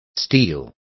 Complete with pronunciation of the translation of steel.